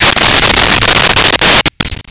Sfx Flame Burst 02
sfx_flame_burst_02.wav